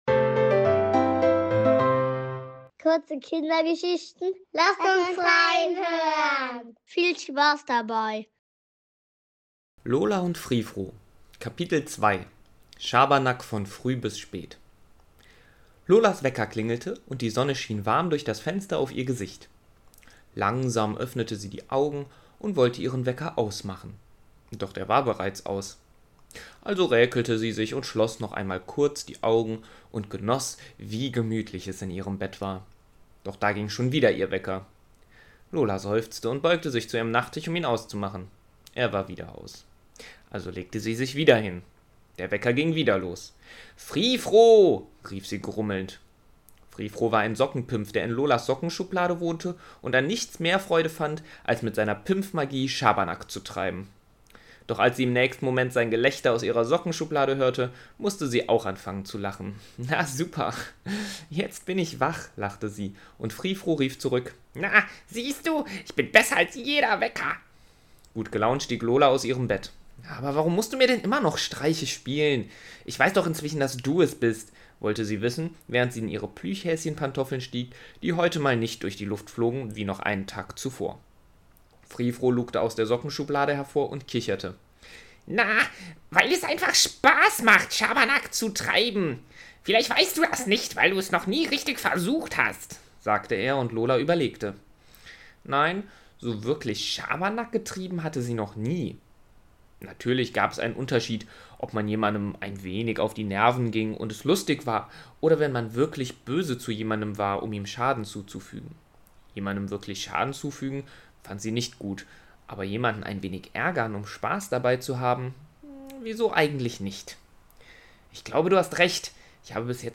Kurze Kindergeschichten zum Entspannen und Einschlafen